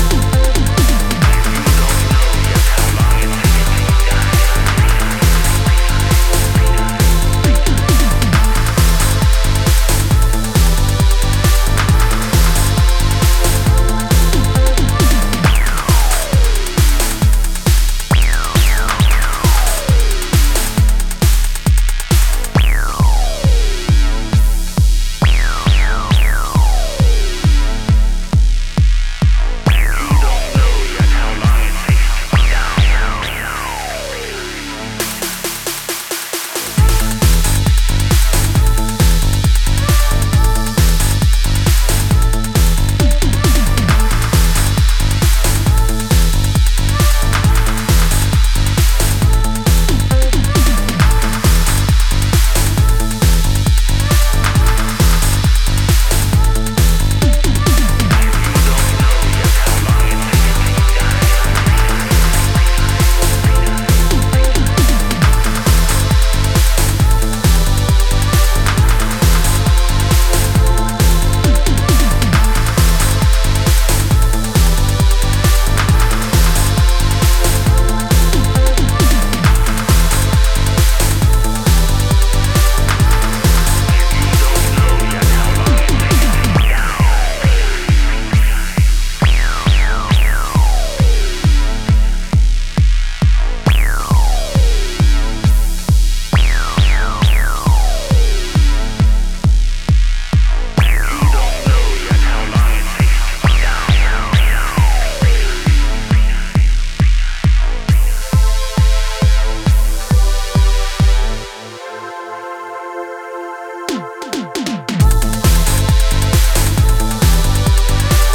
brings a touch of italo-flavored euphoria